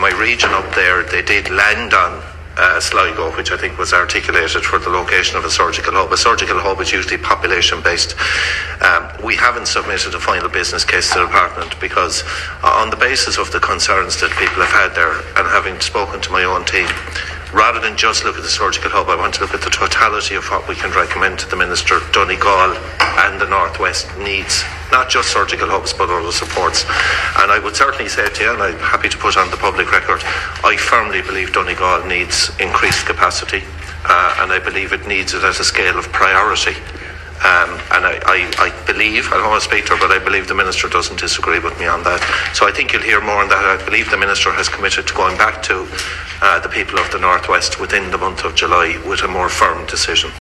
The Chief Executive of the HSE has told a Dail committee today that a business case for a North West surgical hub hasn’t been presented to the Health Minister because they want to address the wider need for services across the region rather than just one facility.
Bernard Gloster told Senator Manus Boyle that he believes Donegal does need more capacity, and there’s an ongoing conversation about how that can be addressed.